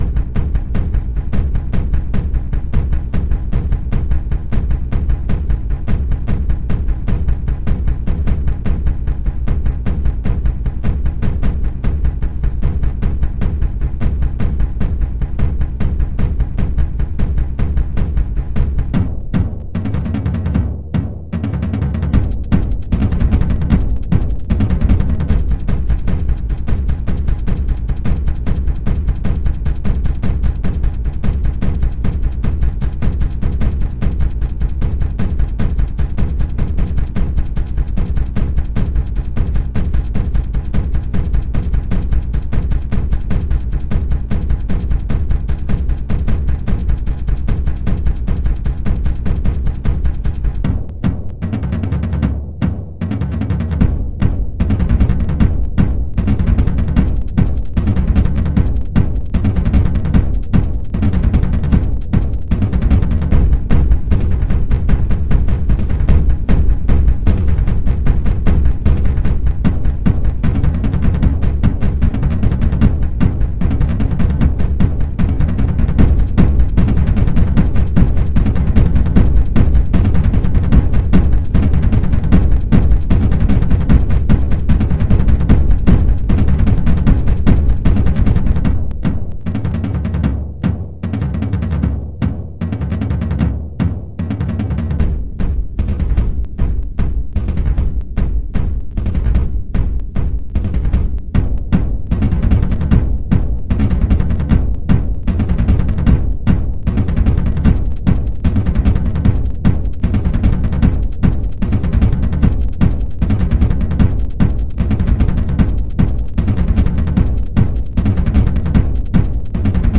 非洲人 房子的鼓声循环B
描述：我在Studio One中设计的声学汤姆和踢腿鼓样本...
Tag: 125 bpm Dance Loops Drum Loops 2.58 MB wav Key : Unknown